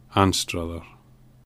Anstruther /ˈænstrəðər/